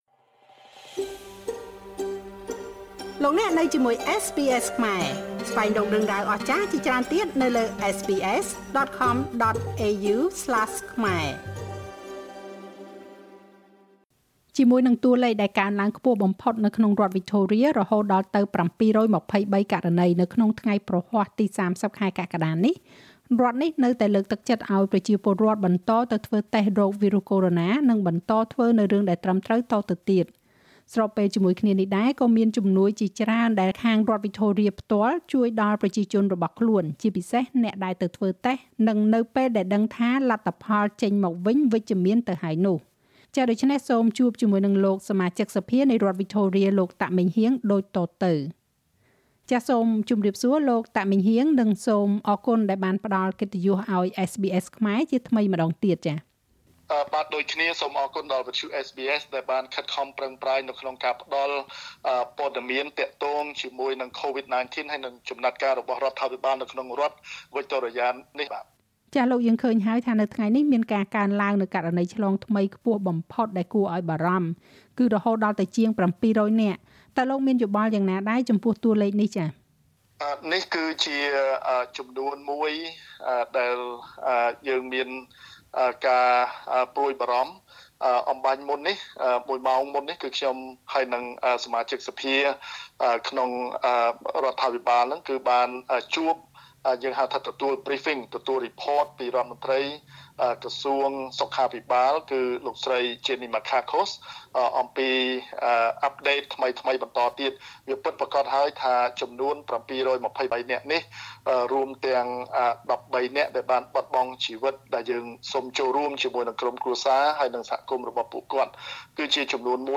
សូមស្តាប់បទសម្ភាសន៍រវាងSBSខ្មែរ និងលោក សមាជិកសភានៃរដ្ឋវិចថូរៀ តាក ម៉េងហ៊ាង ដូចតទៅ។
Victorian MP Meng Heang Tak provides update on Covid-19 Source: Supplied